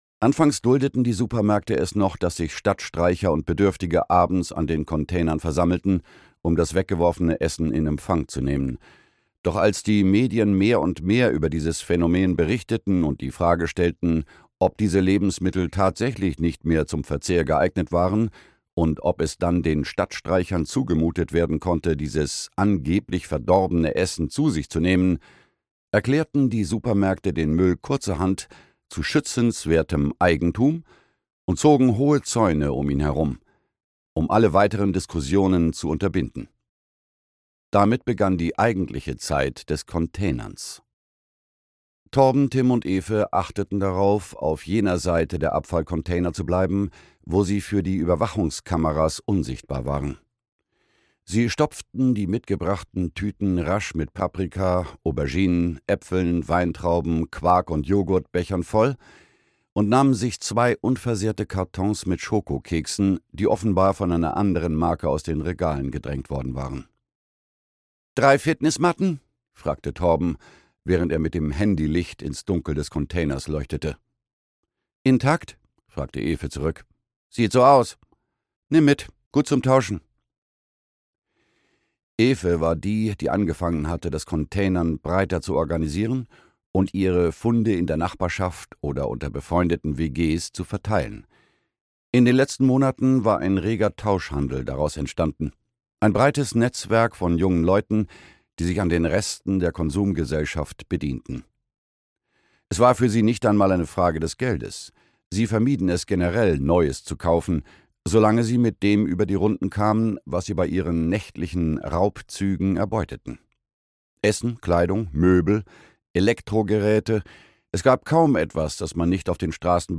Earth – Der Widerstand (Earth 2) - Hansjörg Thurn - Hörbuch